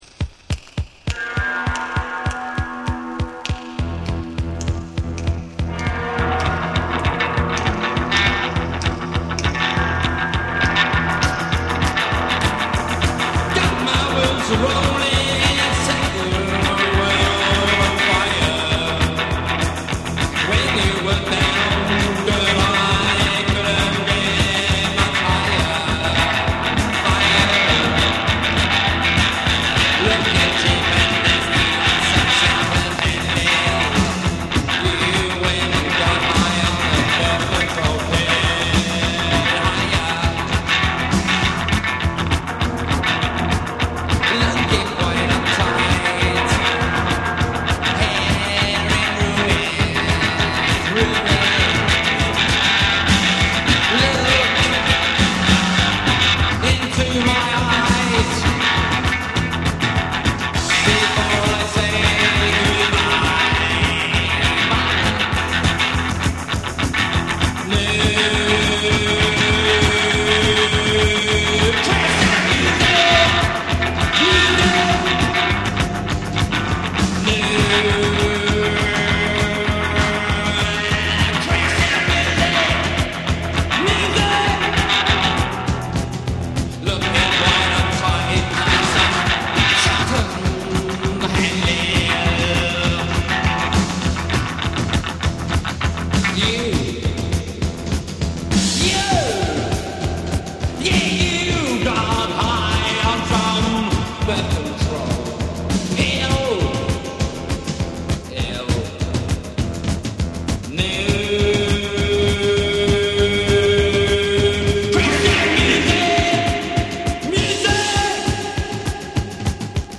NEW WAVE & ROCK